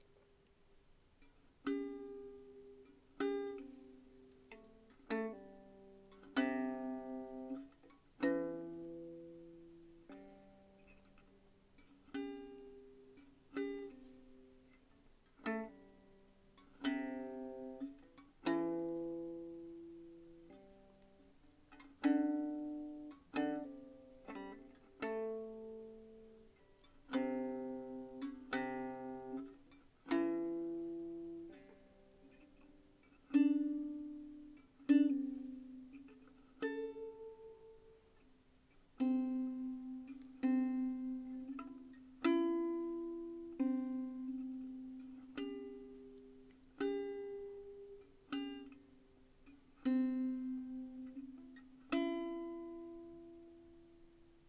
三味線
駒：象牙（オリジナル）